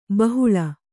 ♪ bahuḷa